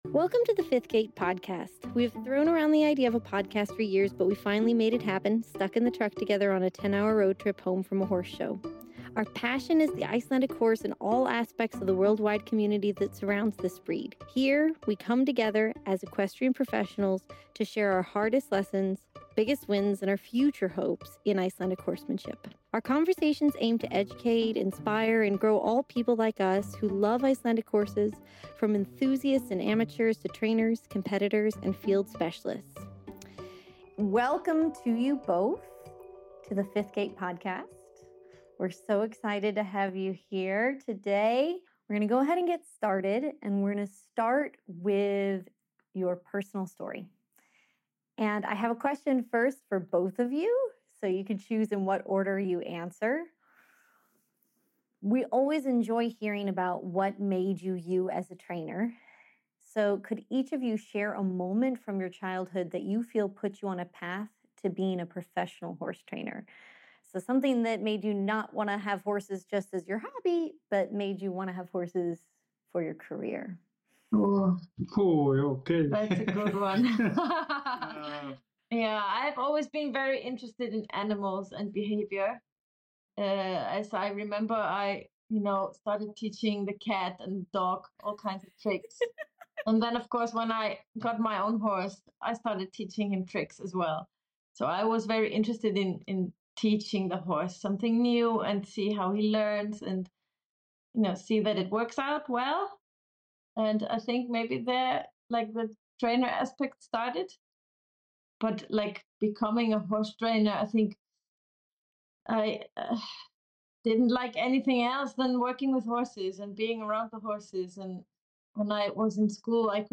In this episode of The Fifth Gait Podcast, hosts sit down with Iceland-based trainers